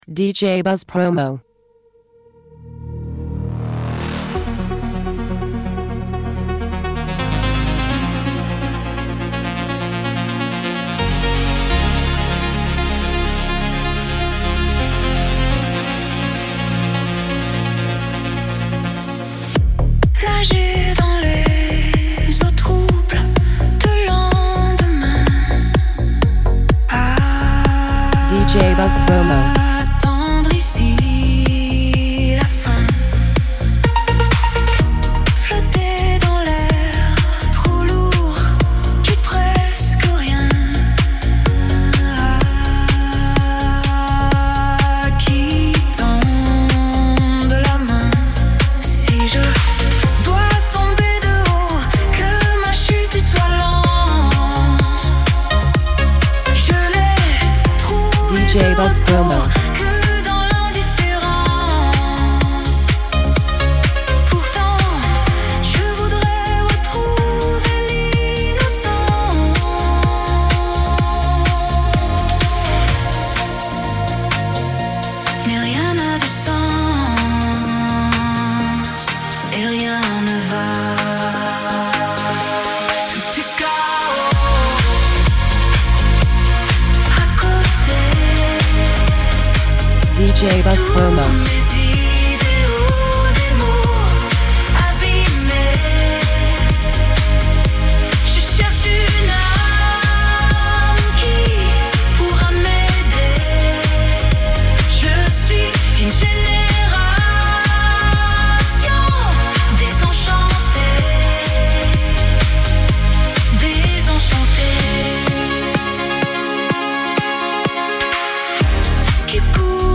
synth-pop/dance track